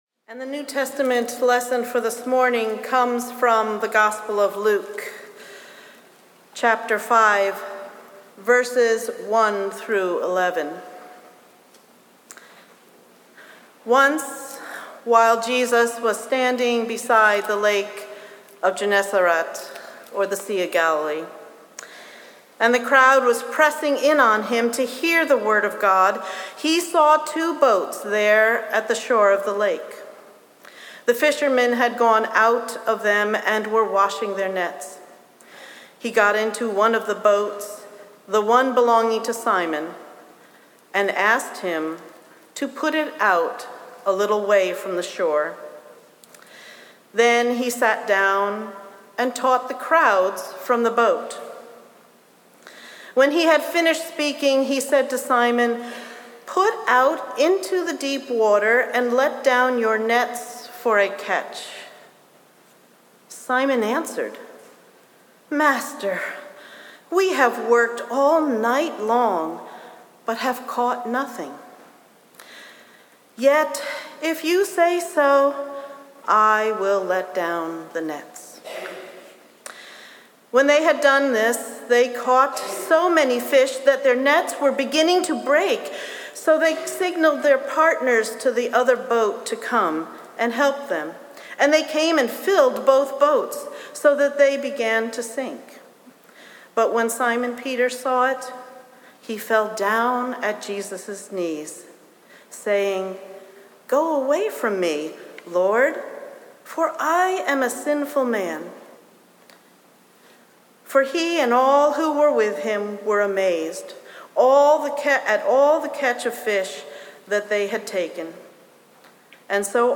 Sermon+8-24-25.mp3